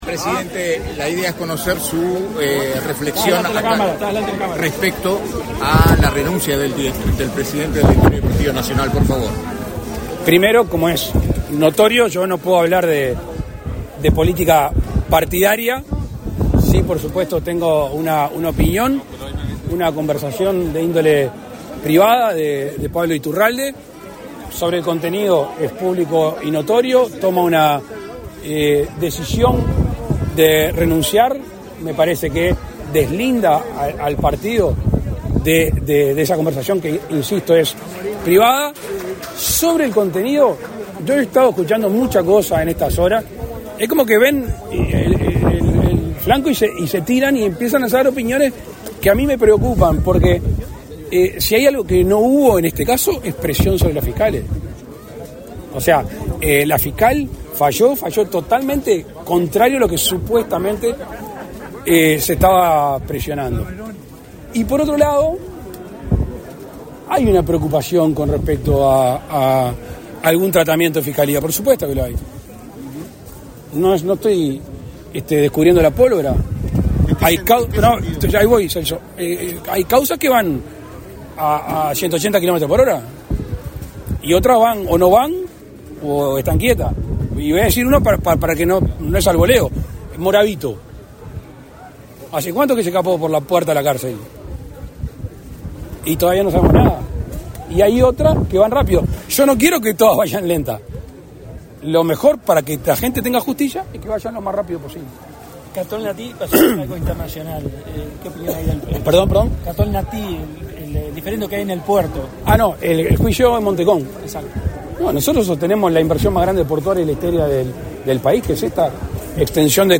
Declaraciones a la prensa del presidente de la República, Luis Lacalle Pou
Declaraciones a la prensa del presidente de la República, Luis Lacalle Pou 24/05/2024 Compartir Facebook X Copiar enlace WhatsApp LinkedIn Tras participar en la inauguración de soluciones habitacionales de Mevir en Chuy, en el departamento de Rocha, este 24 de mayo, el presidente de la República, Luis Lacalle Pou, realizó declaraciones a la prensa.